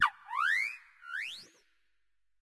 Cri de Dofin dans Pokémon Écarlate et Violet.
Cri_0963_EV.ogg